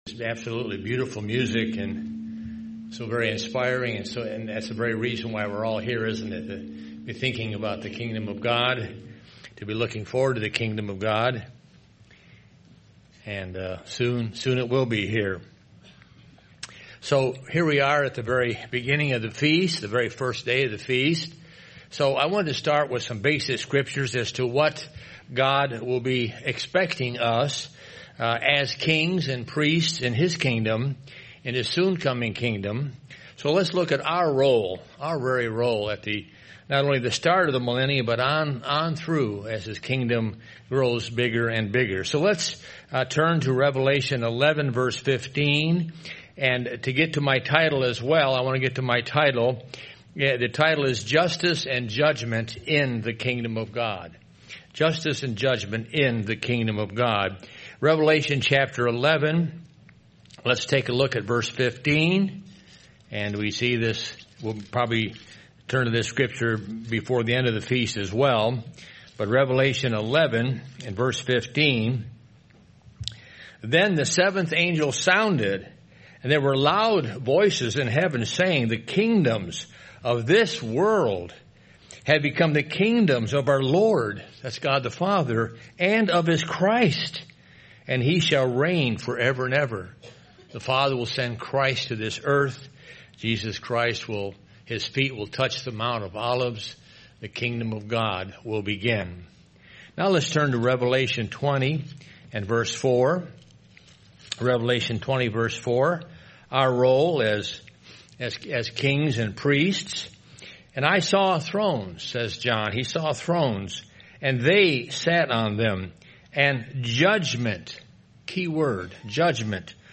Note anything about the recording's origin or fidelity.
This sermon was given at the Branson, Missouri 2023 Feast site.